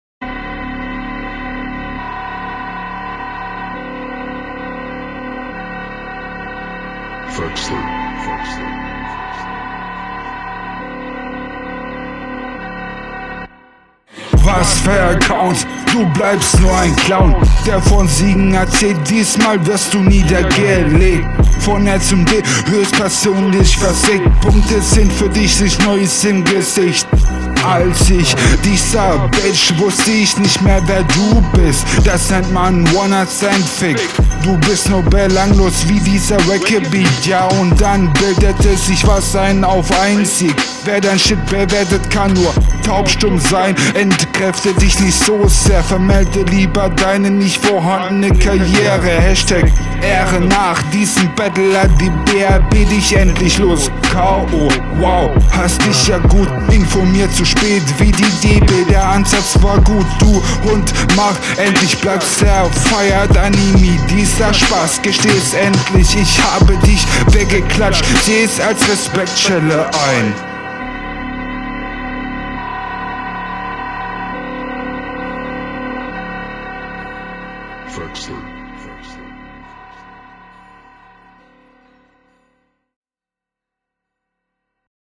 Du bist ganz offensichtlich (noch) unsicher und öfter mal offpoint.
Atmer rausschneiden und die Doubles wirken sehr unsauber oder nicht ganz genau drauf